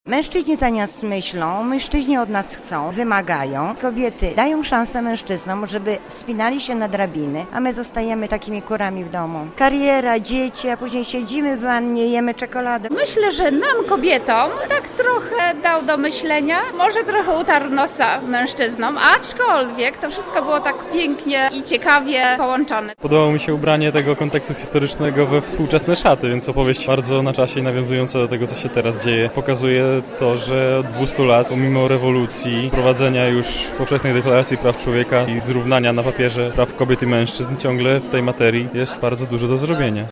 Spektakl dał nam wiele do myślenia– mówili widzowie, zarówno kobiety, jak i mężczyźni.
relacja Konfrontacje Teatralne